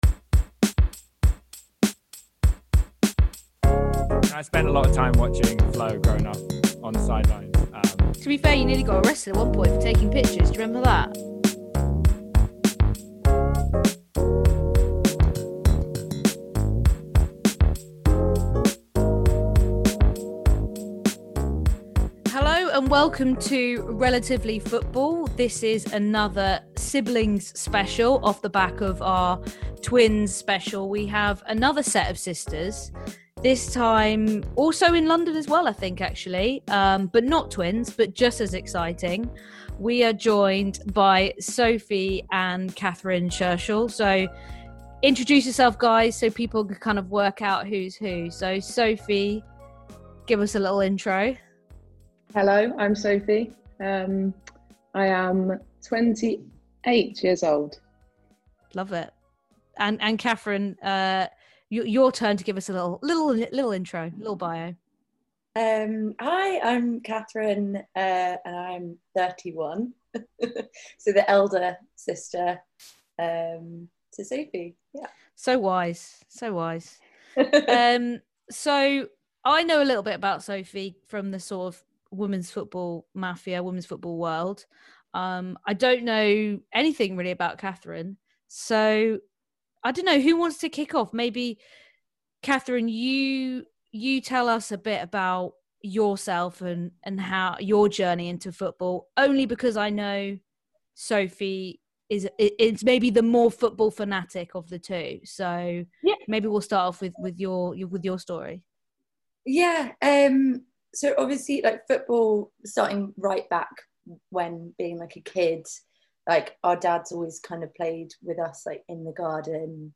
Sibs Interview